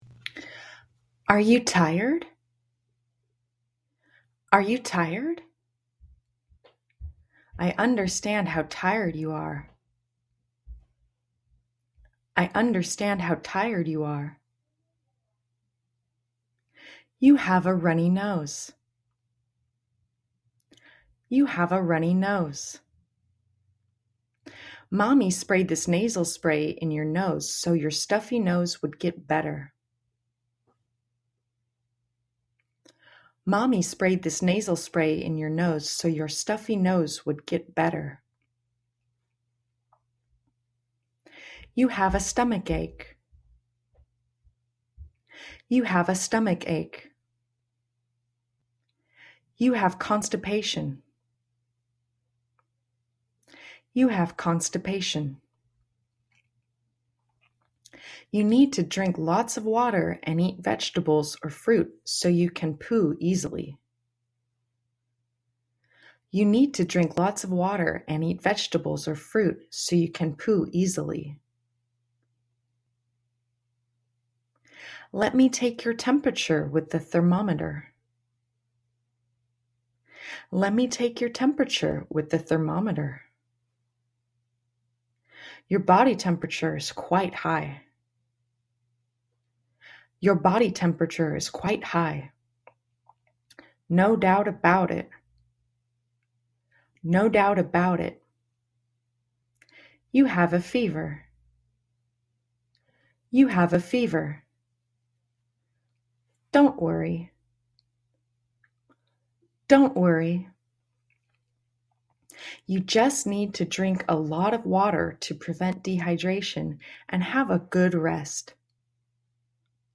Mẫu hội thoại xoay quanh cuộc sống hằng ngày của con, được ghi âm trực tiếp từ người Mỹ không những giúp phụ huynh ôn tập mà còn giúp luyện nói, phát âm và nghe.